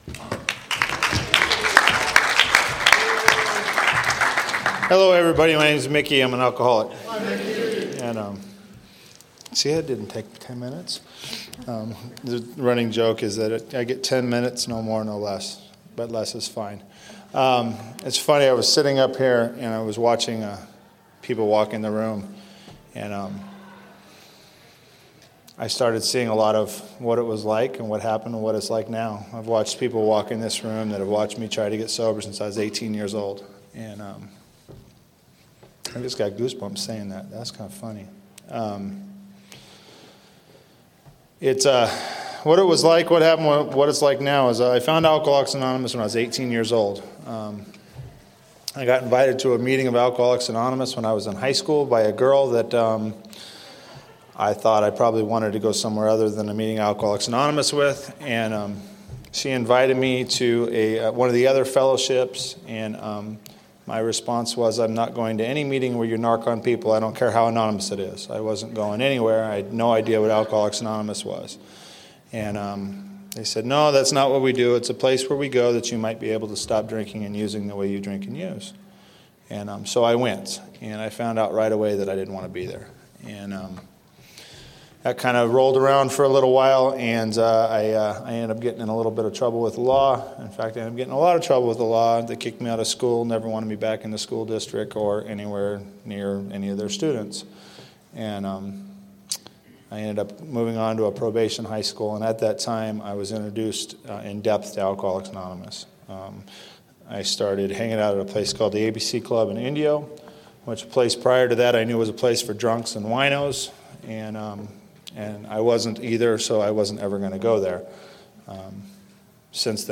Four 10 Minute AA Speakers - MAAD DOG DAZE 2007